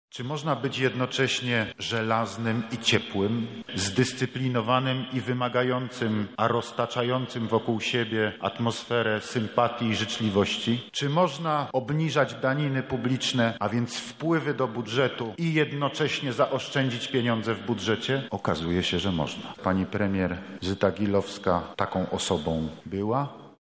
W Świdniku pożegnano prof. Zytę Gilowską
Żegnamy dziś osobę nietuzinkową, która jako minister finansów dokonała niezwykłych rzeczy – mówił podczas uroczystości Prezydent Andrzej Duda.